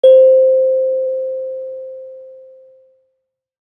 kalimba1_circleskin-C4-mf.wav